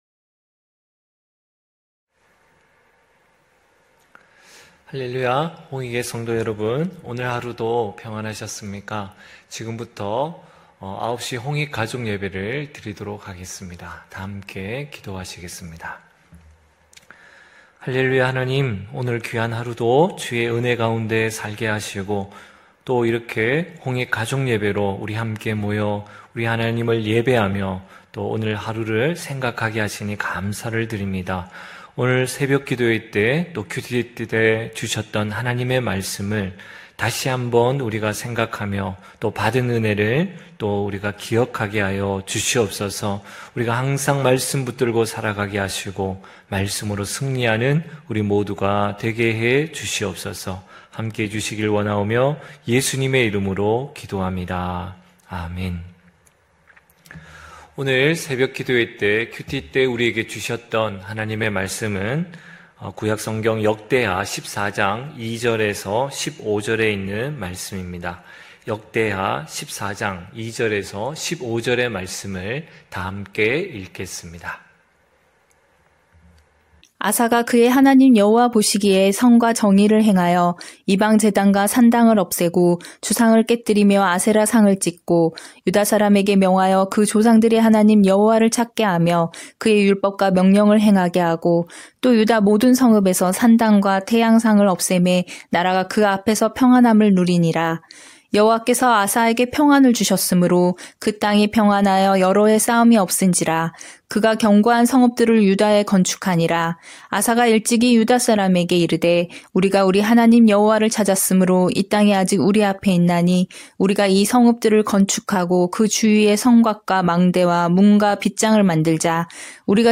9시홍익가족예배(11월17일).mp3